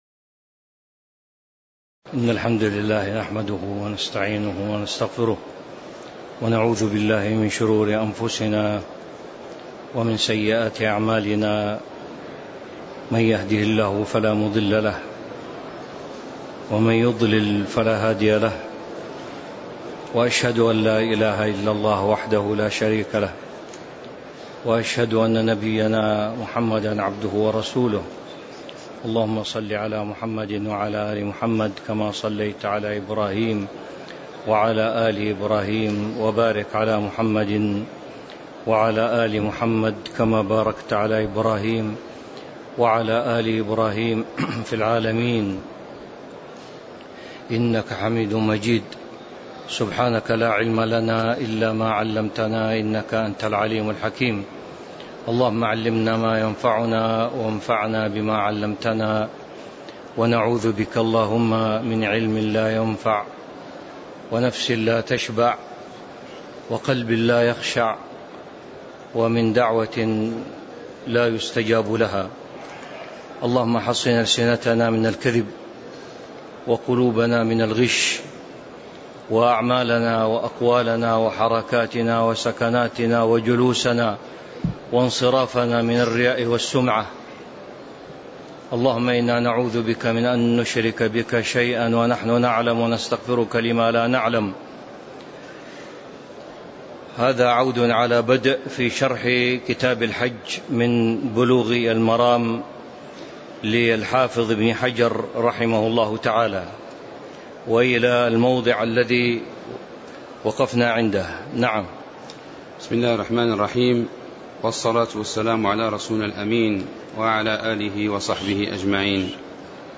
تاريخ النشر ٣٠ ذو القعدة ١٤٤٣ هـ المكان: المسجد النبوي الشيخ